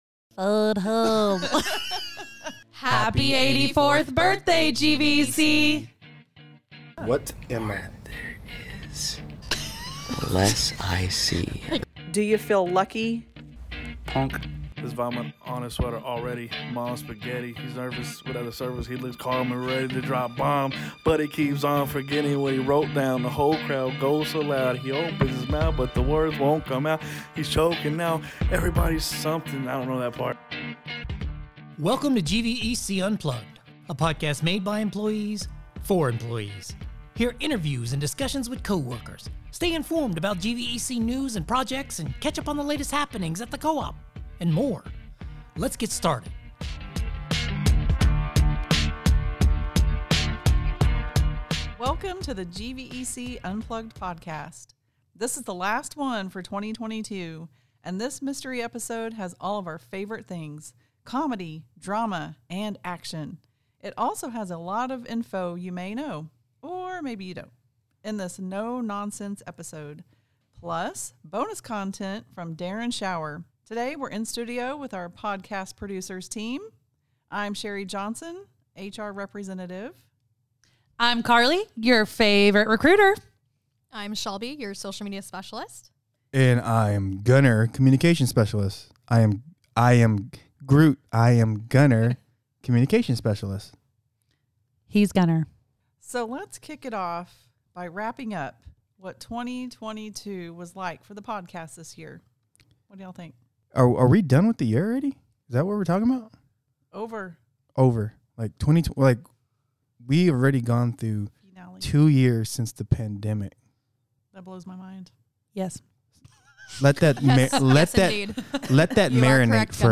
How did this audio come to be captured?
The podcast producers took to the halls of GVEC to quiz coworkers about their knowledge no-how or no-not.